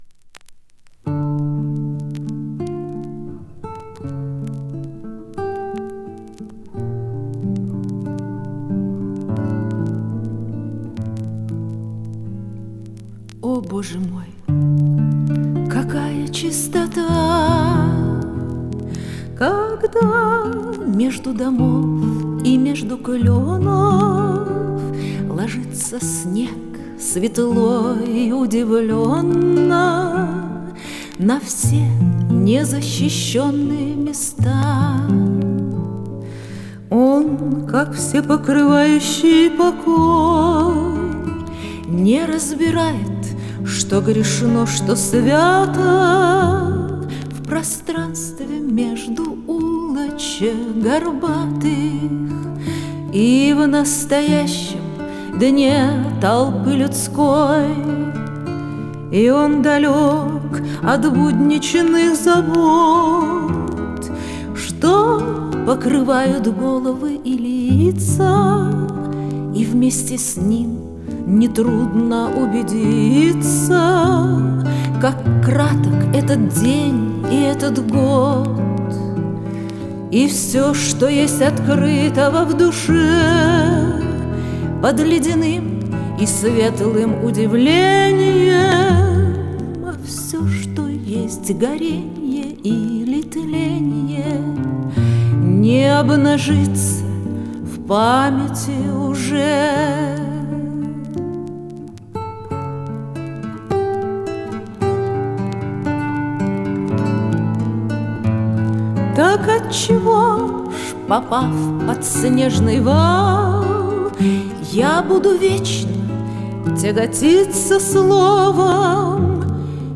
Жанр: Romance